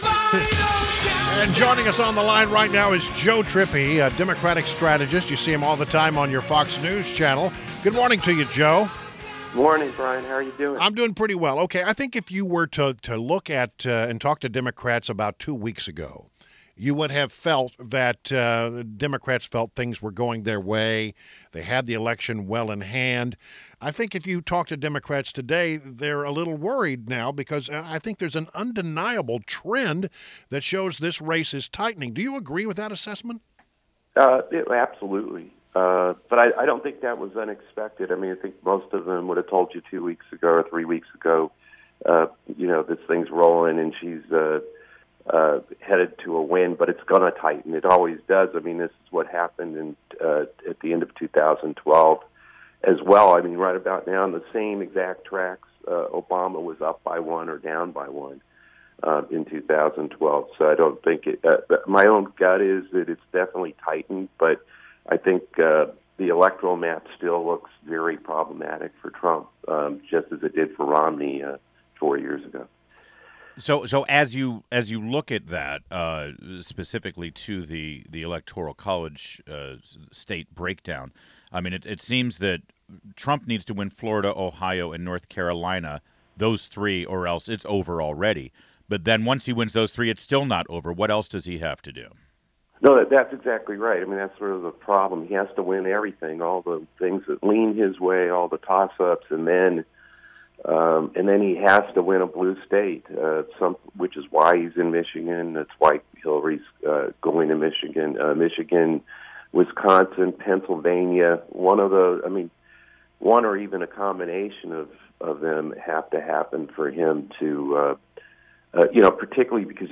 WMAL Interview - JOE TRIPPI - 11.03.16
JOE TRIPPI – Democratic Strategist and Fox News Contributor – discussed up the state of the election.